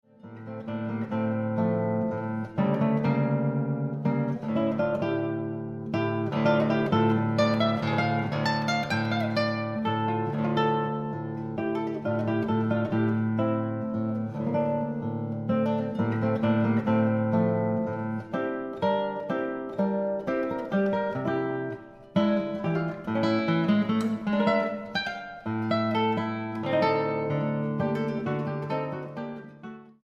danza oriental